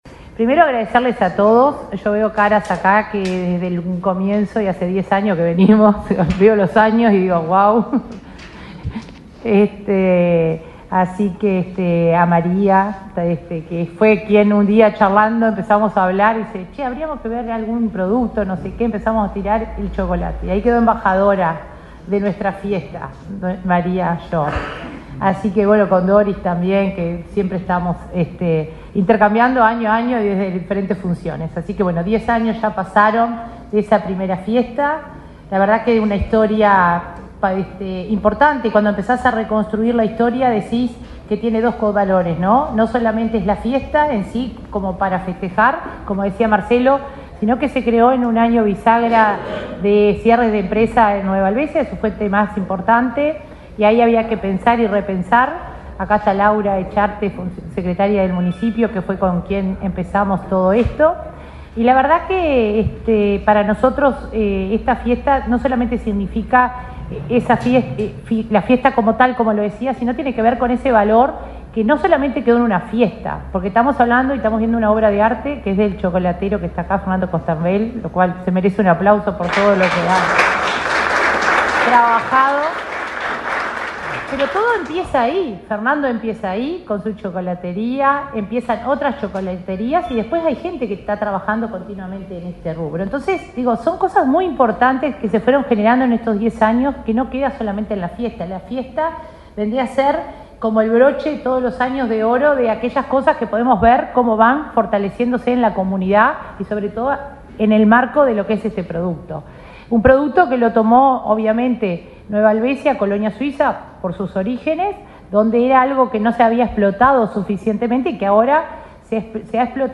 Palabras de autoridades en acto en el Ministerio de Turismo
La coordinadora general del Área de Descentralización de la Oficina de Planeamiento y Presupuesto, María de Lima, y el ministro de Turismo, Eduardo Sanguinetti, participaron, este miércoles 10 en Montevideo, en el lanzamiento de la 10.ª edición de la Fiesta Nacional del Chocolate, que se realizará el 21 de julio en Nueva Helvecia, departamento de Colonia.